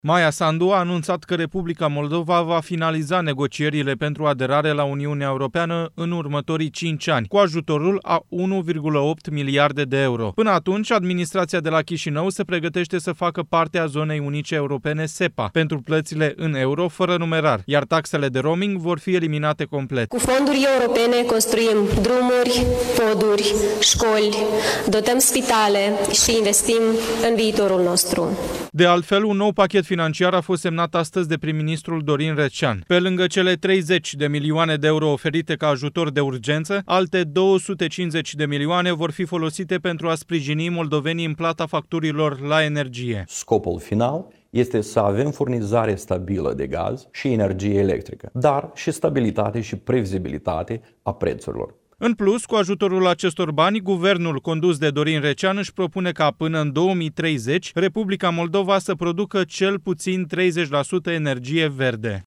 Maia Sandu a anunțat că Republica Moldova va finaliza negocierile pentru aderare la Uniunea Europeană în următorii cinci ani, cu ajutorul a 1,8 miliarde de euro, la o conferință de presă alături de comisara europeană pentru Extindere, Marta Kos.